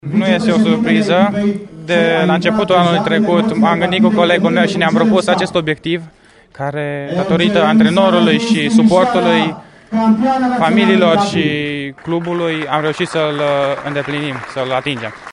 declarație audio mai jos